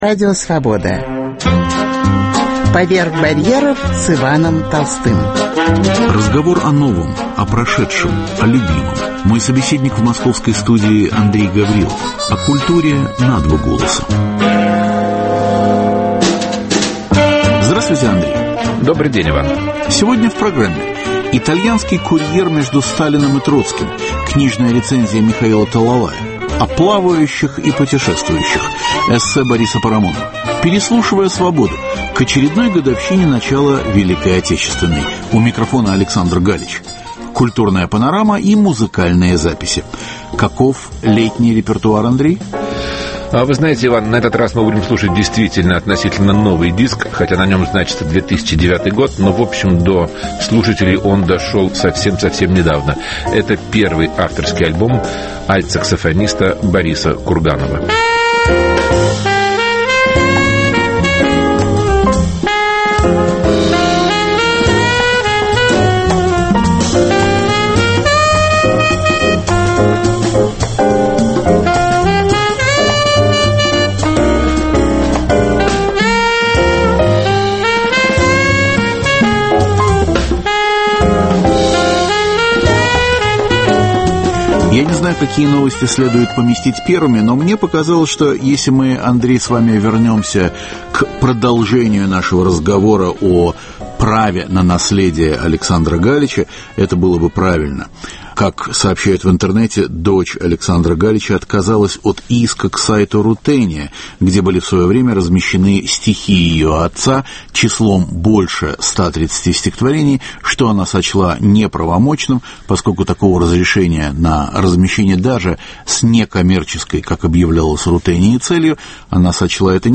Переслушивая Свободу: к очередной годовщине начала Великой Отечественной: у микрофона Александр Галич. Культурная панорама и музыкальные записи.